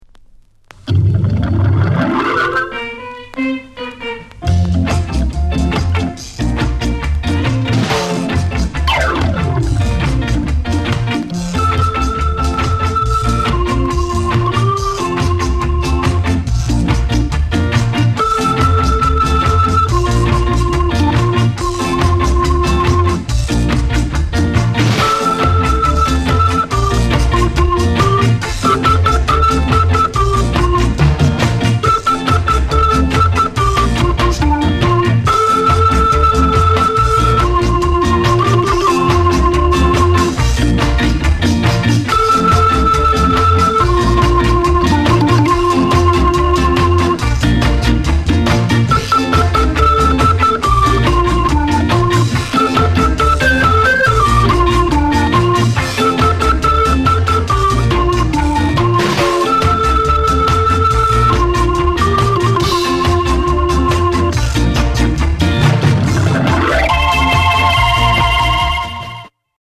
SKINHEAD INST